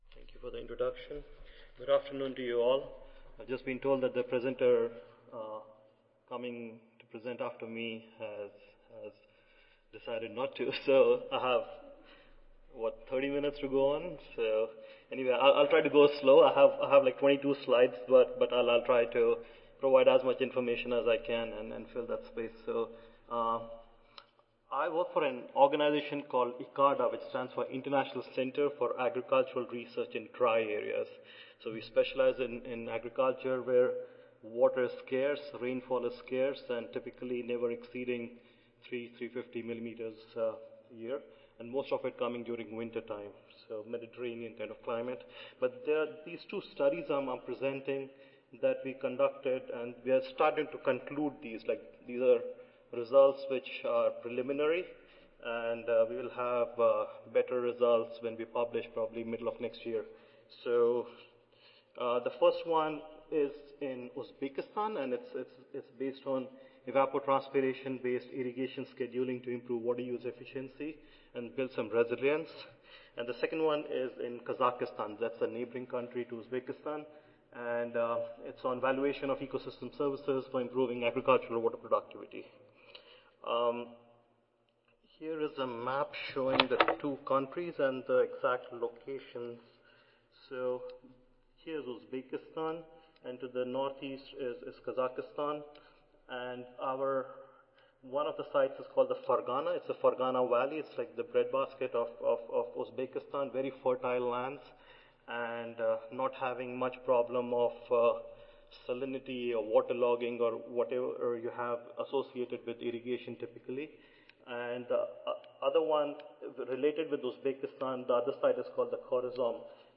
See more from this Division: SSSA Division: Soil and Water Management and Conservation See more from this Session: Soil and Water Management Conservation Oral III